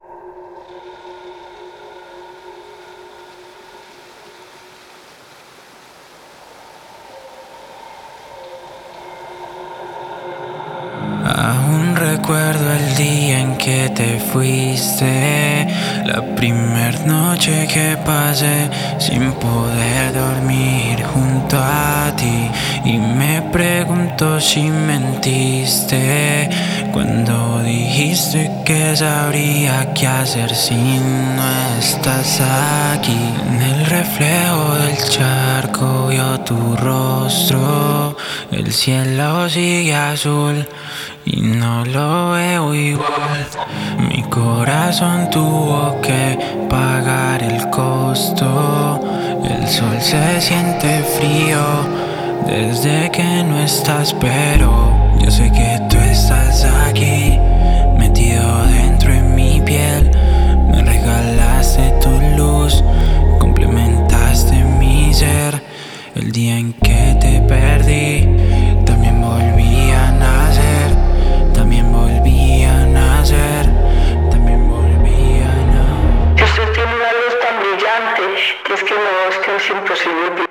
Música urbana